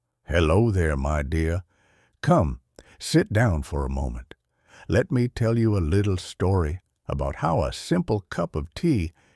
平静冥想配音：为正念打造的自然AI旁白
使用专为引导式冥想、睡眠故事和健康应用设计的温和、自然的AI声音，创造沉浸式正念体验。
文本转语音
舒缓节奏
温和人声
引导式冥想